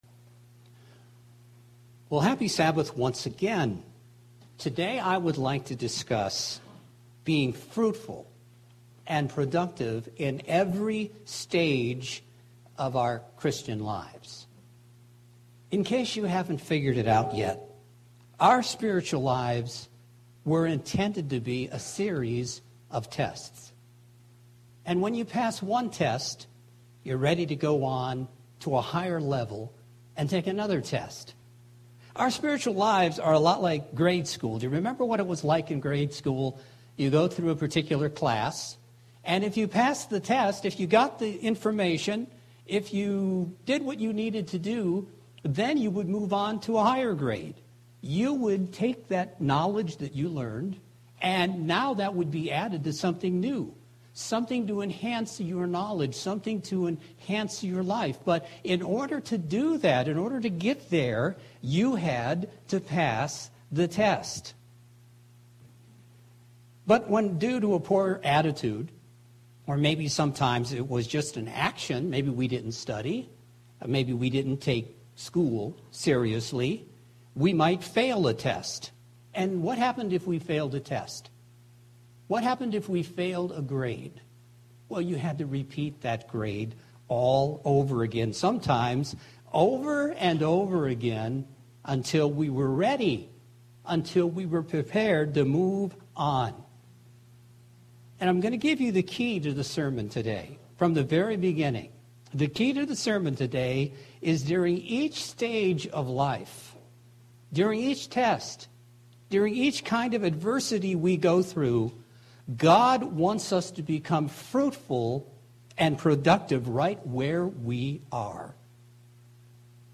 Here is the key point of my Sermon today… during each stage or adversity we go through... God wants us to become fruitful and productive right where we are.